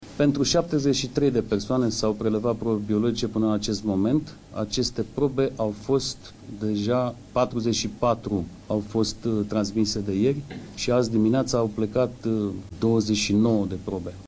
Prefectul de Gorj, Cristinel Rujan, a declarat că autorităţile continuă ancheta pentru a-i depista pe toți cei care au intrat în contact cu cele trei persoane care au coronavirus şi care au umblat prin judeţ recent: doi italieni şi un român: